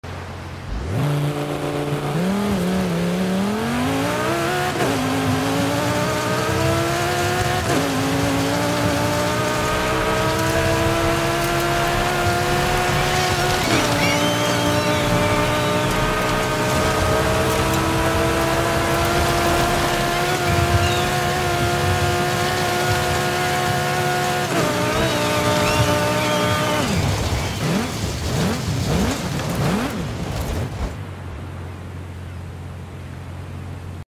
2012 Lamborghini Gallardo LP 570 4 Sound Effects Free Download